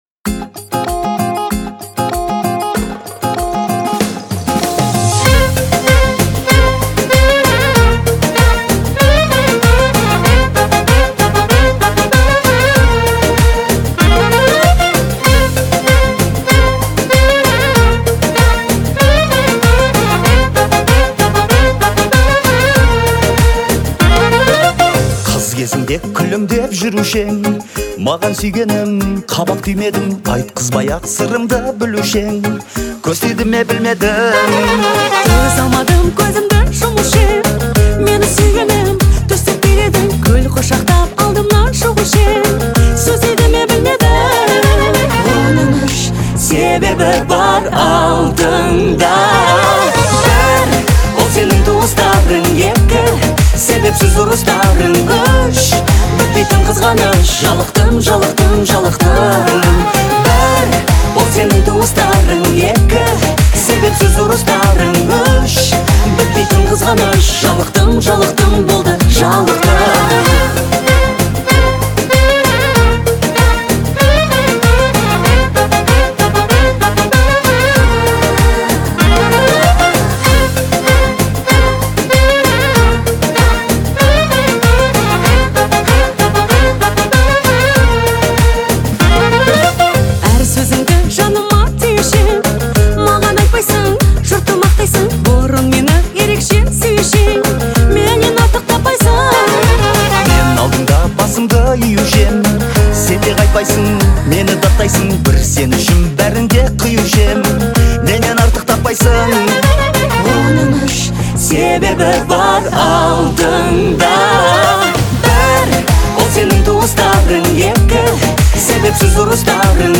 это яркая композиция в жанре поп